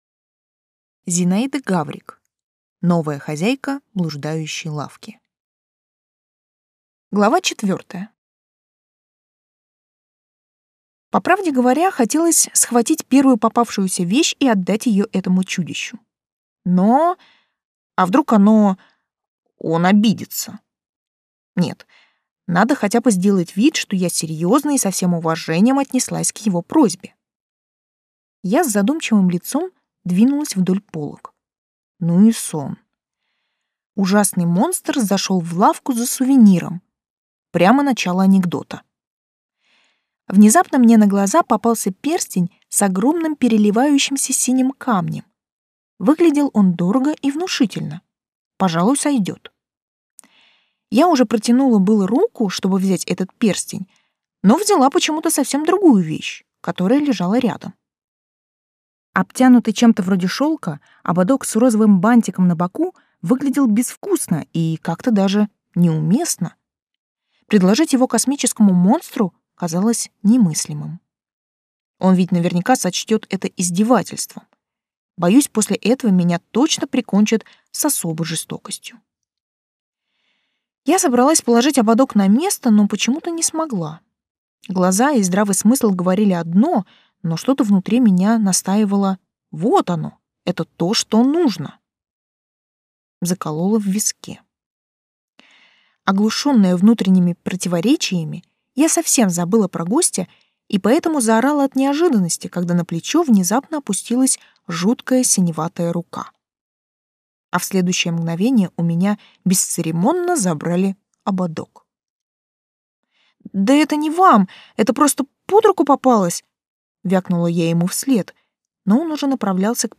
Аудиокнига Новая хозяйка блуждающей лавки | Библиотека аудиокниг